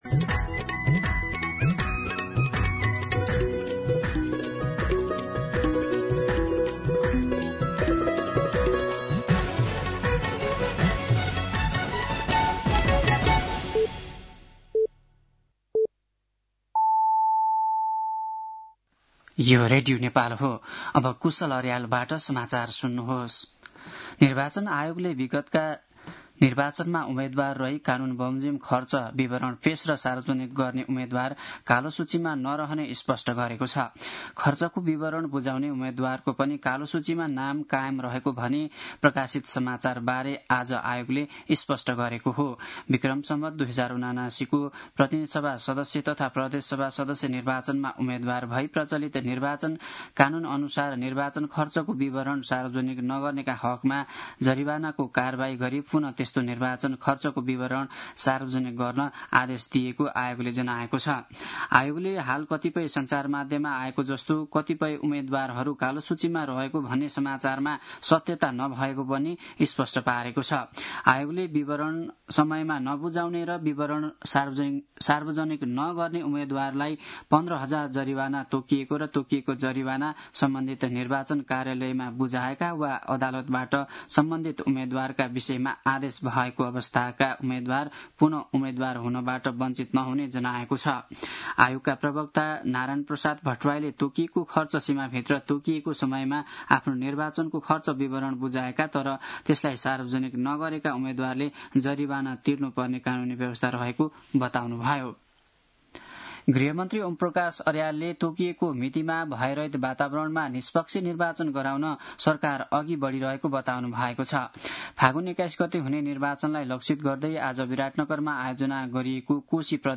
दिउँसो ४ बजेको नेपाली समाचार : १६ पुष , २०८२
4-pm-news-9-16.mp3